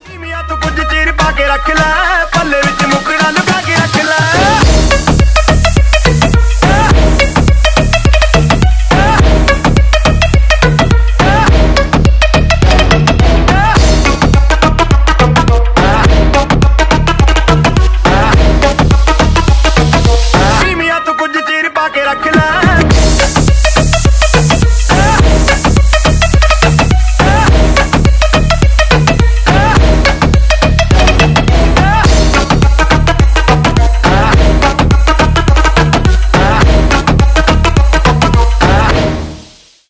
Ремикс
Индийские
громкие
клубные